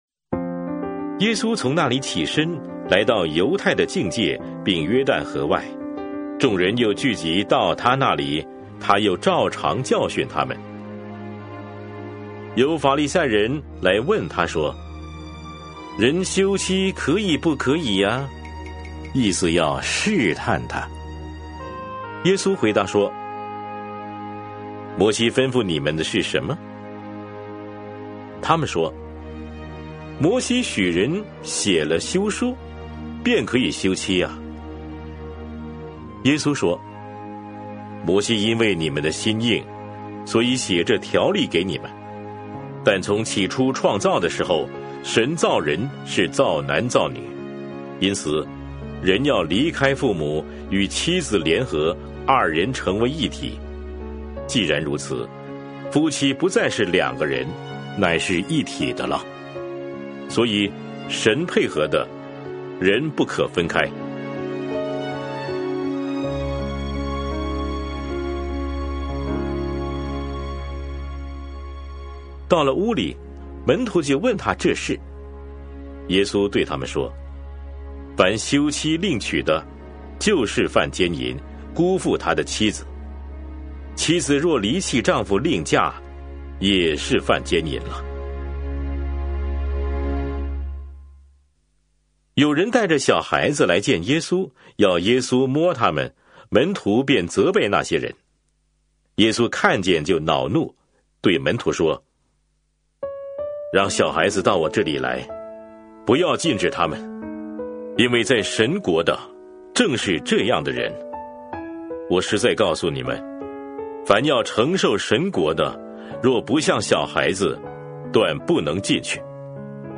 每日读经 | 马可福音10章